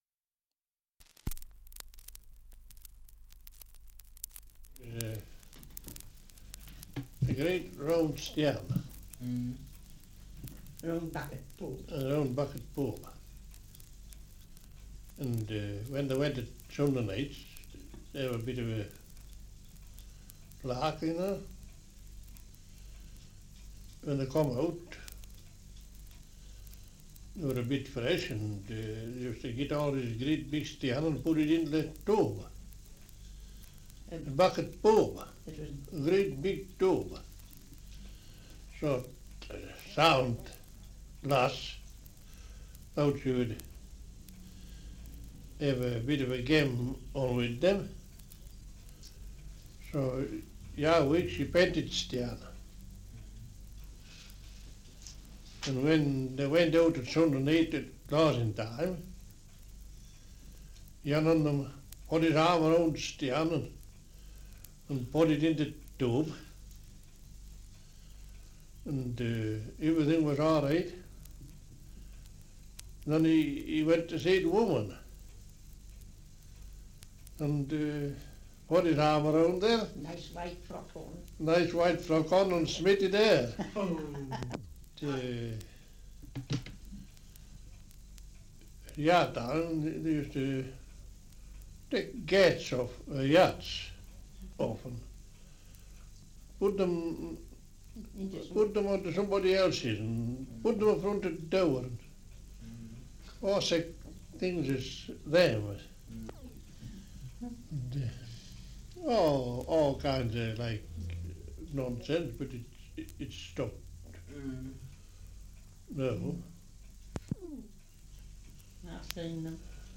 Survey of English Dialects recording in Threlkeld, Cumberland
78 r.p.m., cellulose nitrate on aluminium